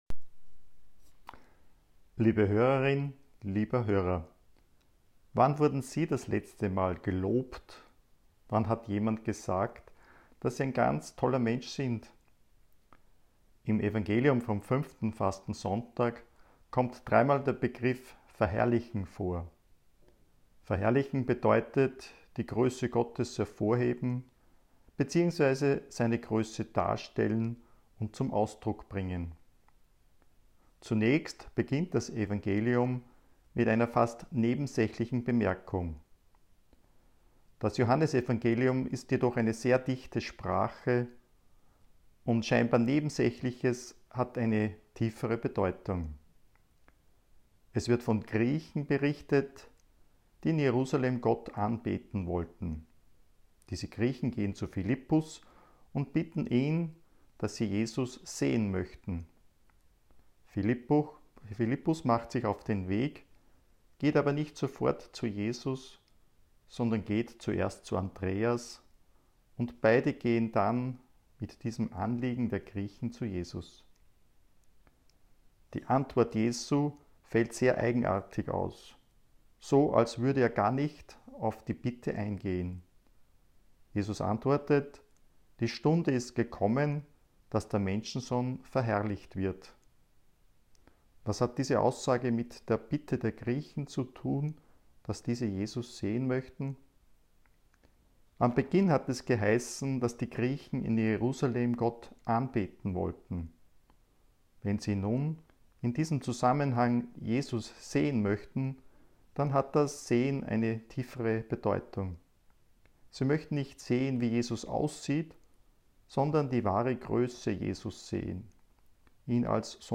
Predigt_5__FaSo_m4a